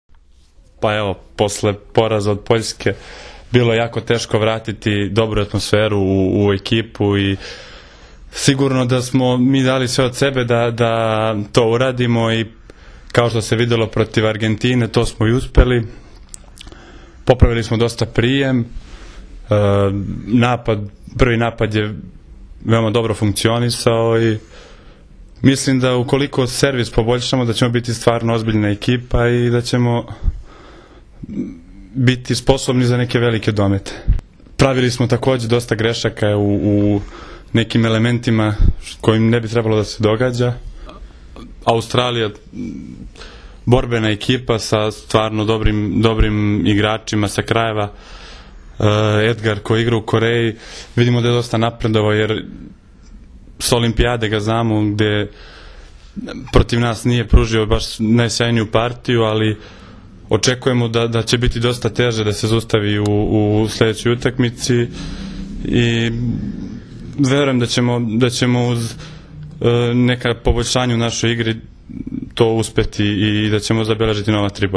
IZJAVA SREĆKA LISNICA